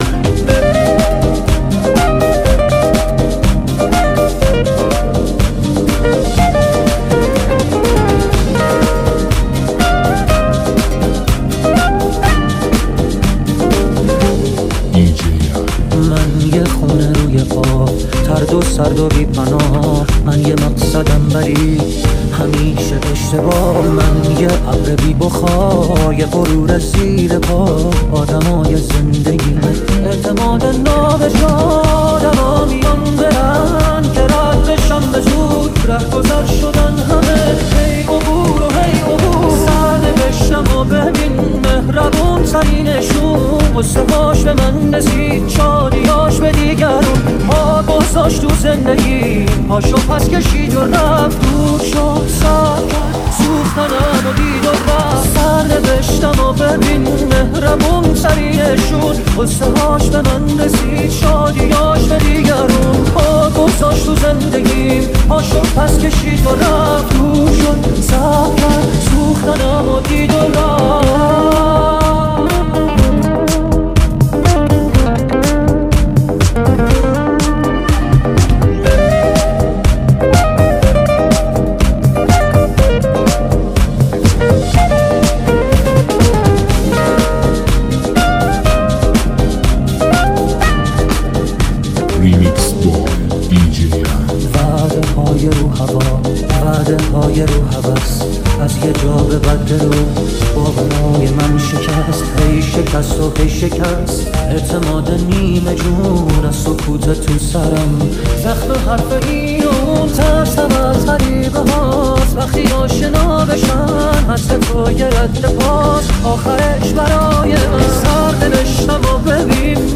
لذت بردن از موسیقی پرانرژی و بیس قوی، هم‌اکنون در سایت ما.